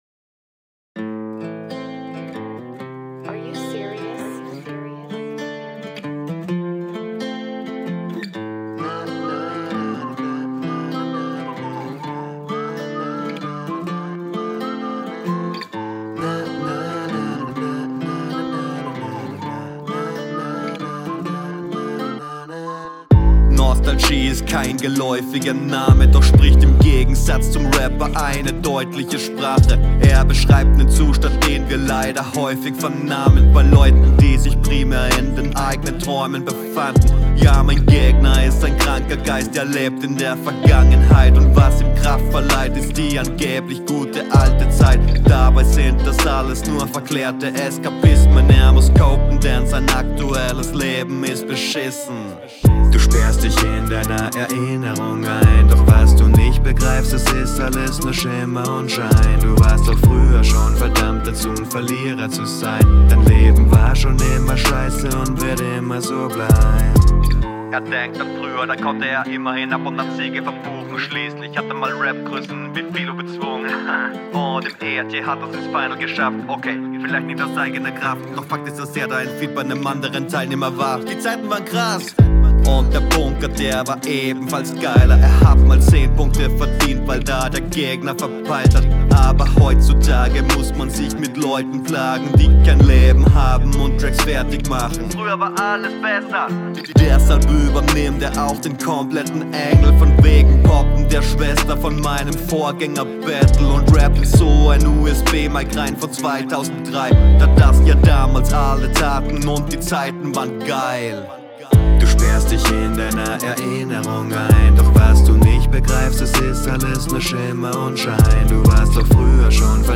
Beste Runde des Battles, Hook wieder sehr gut